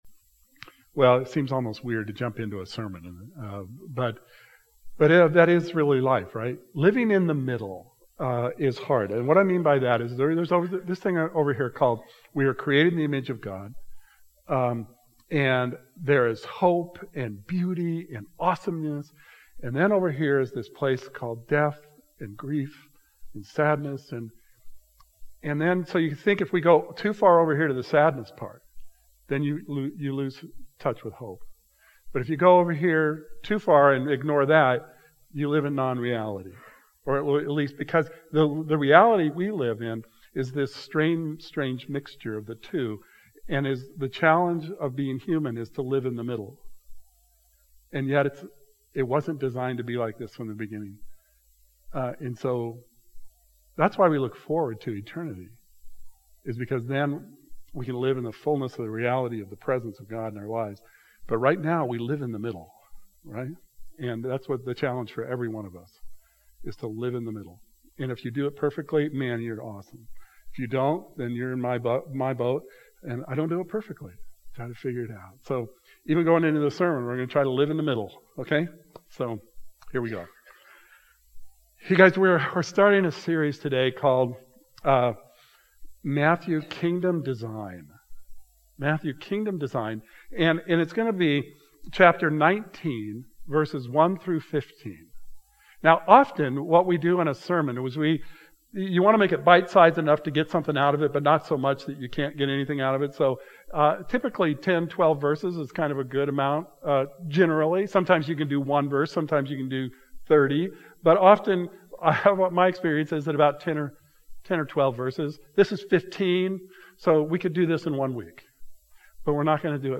This morning's message starts our new series, Matthew: Kingdom Design... looking at Matthew 19.